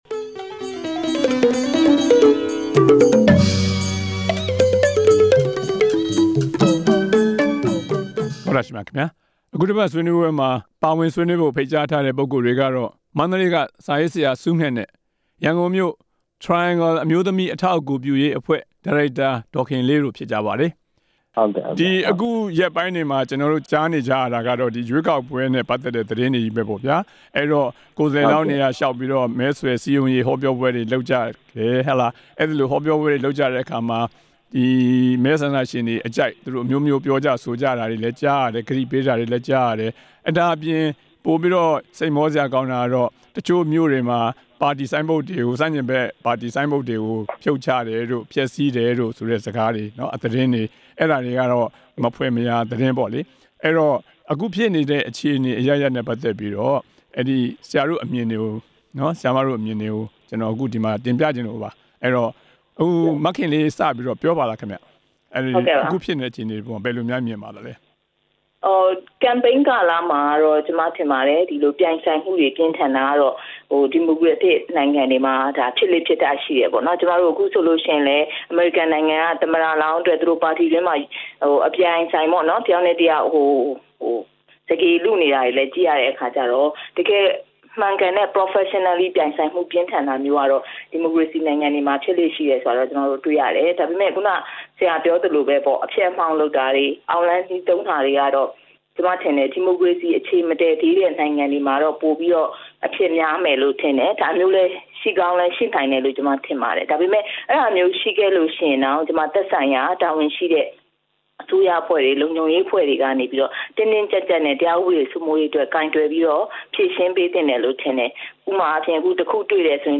ရွေးကောက်ပွဲနီးလာချိန် အဖျက်လုပ်ငန်းများ ပေါ်လာတဲ့အကြောင်း ဆွေးနွေးချက်